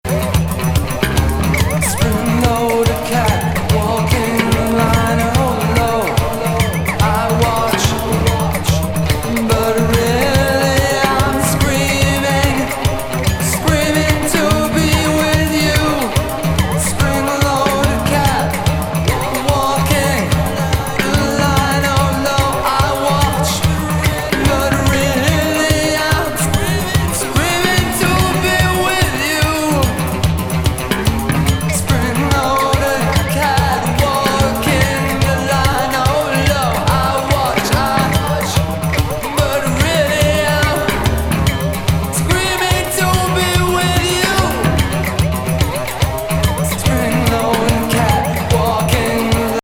エスノ・ポリリズミック・